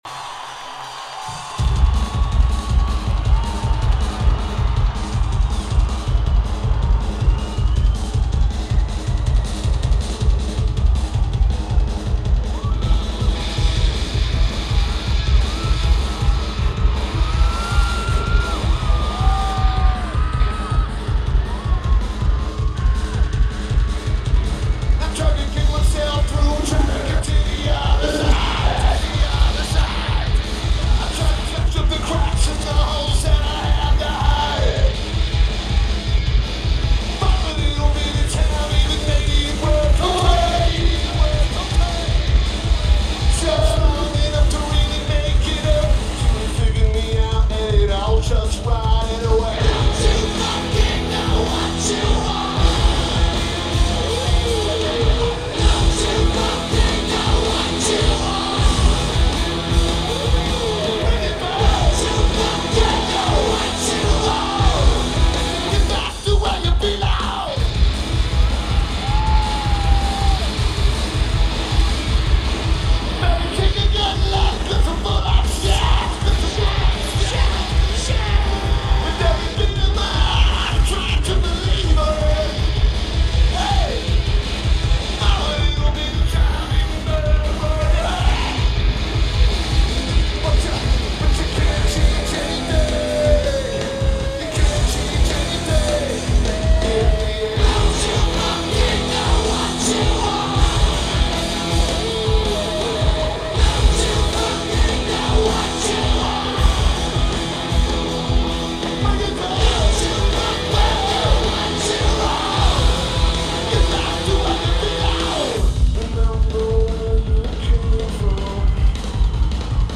Red Rocks Amphitheatre
Lineage: Audio - AUD (AT853 + SP-BB + JB3)